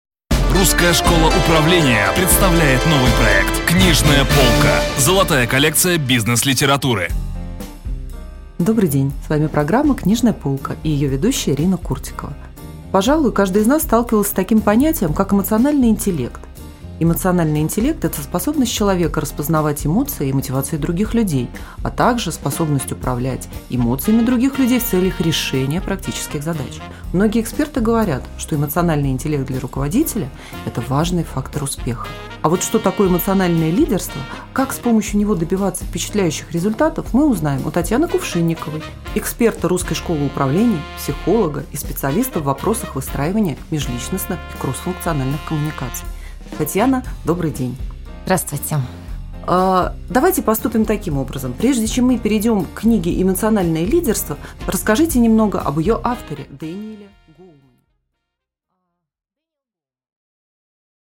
Аудиокнига Обзор книги Д. Гоулмана «Эмоциональное лидерство» | Библиотека аудиокниг